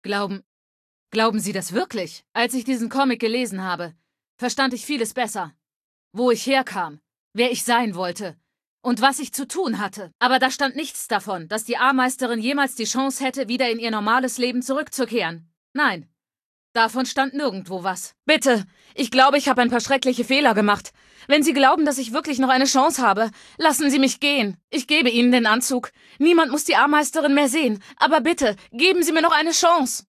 Datei:Femaleadult01default ms02 ms02antorigin2a 000b60cd.ogg
Fallout 3: Audiodialoge